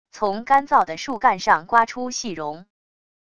从干燥的树干上刮出细绒wav音频